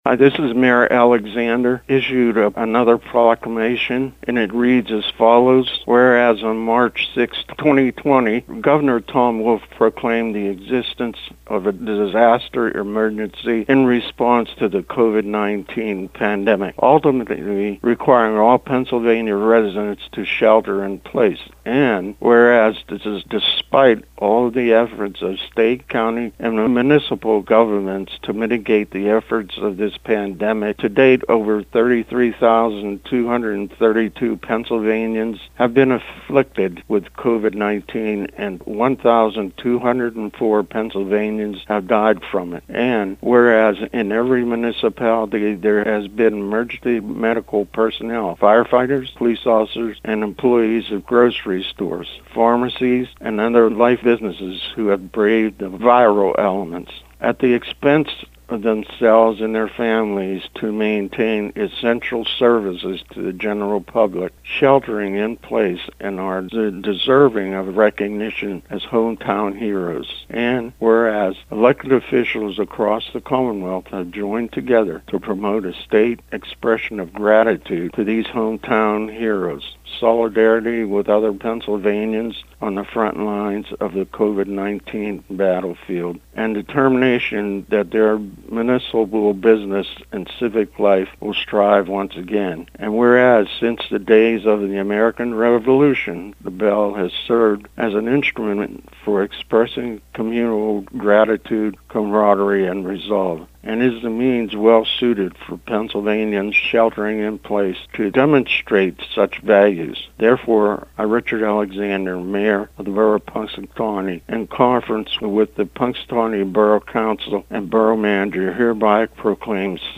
Punxsutawney mayor Richard Alexander and Big Run mayor Joseph L. Butebaugh issued proclamations about the special day.
Punxsy-Mayor.mp3